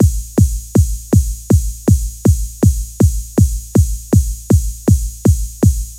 俱乐部踢踏舞
描述：一个简单的节拍，由低音和骑手组成。这个节拍将你的低频发挥到极致
Tag: 160 bpm Dance Loops Drum Loops 1.01 MB wav Key : Unknown